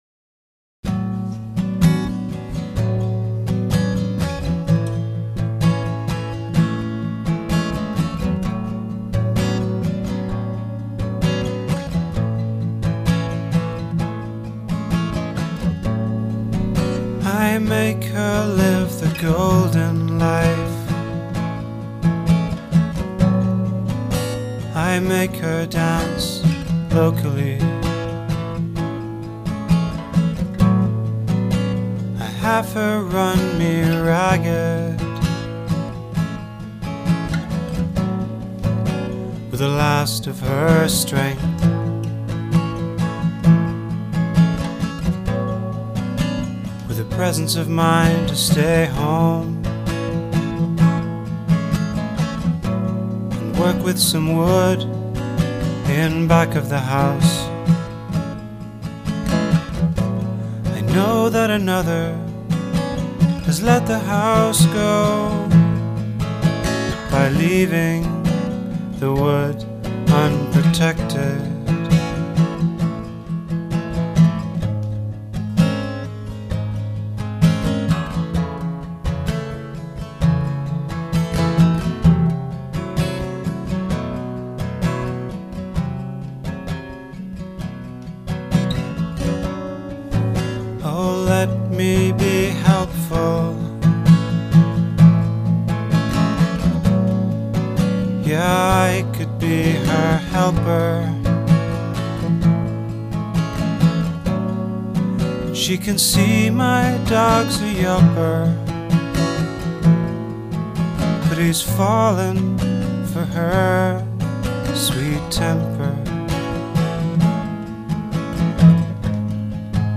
handmade (old sketches, demos, errors)